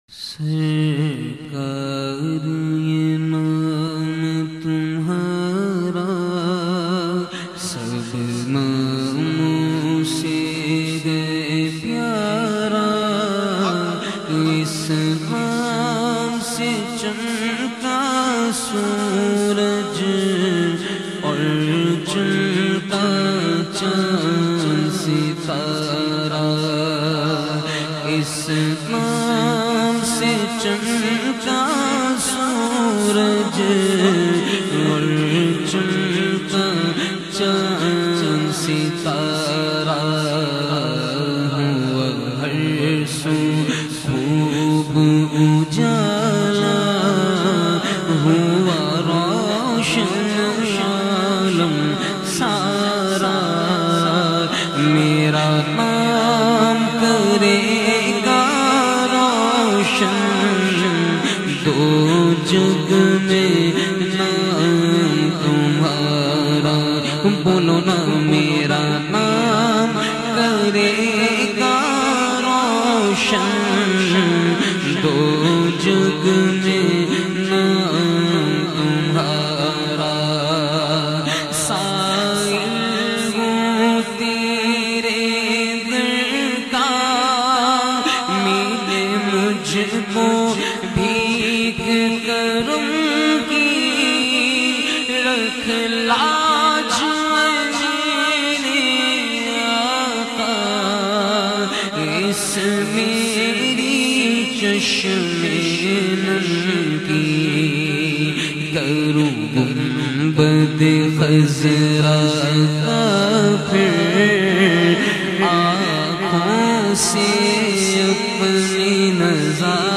Heart-Touching Voice